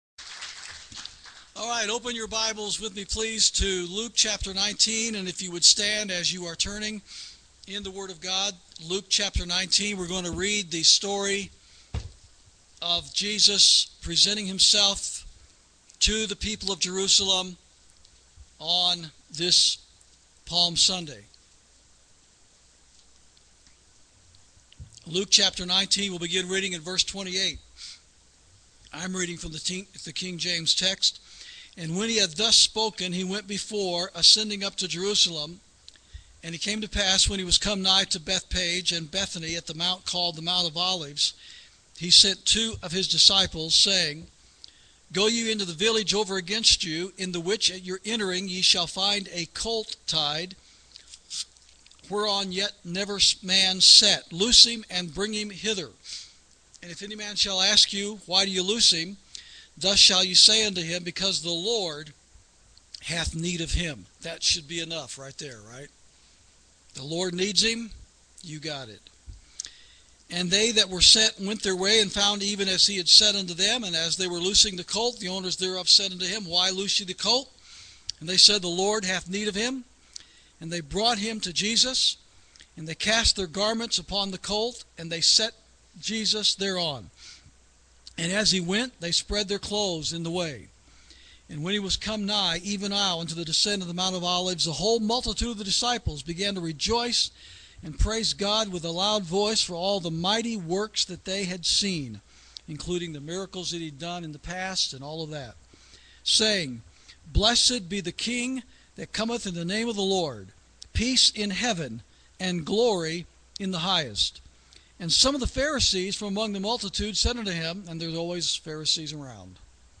Sermons > When The Stones Cry Out
This message was preached by Pastor Chuck Baldwin on Sunday, April 17, 2011, during the service at Liberty Fellowship.